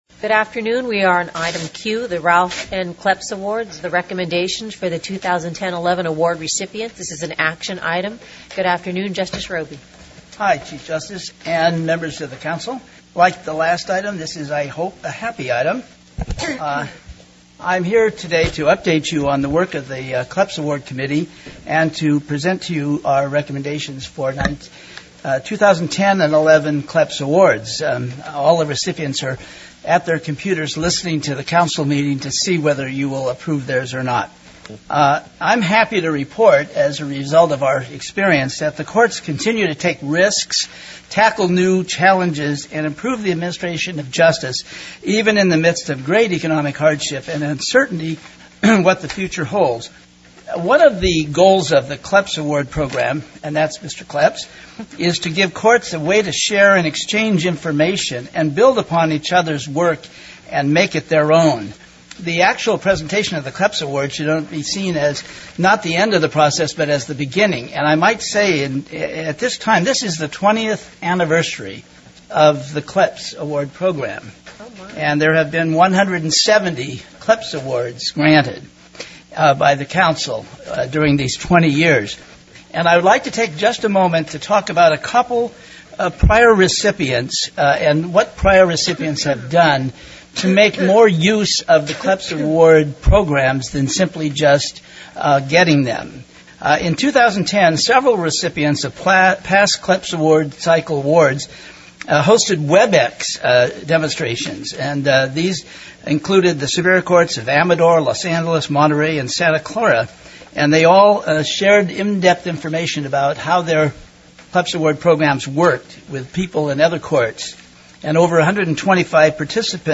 Public Meeting Audio Archive (MP3)